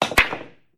Billiards.ogg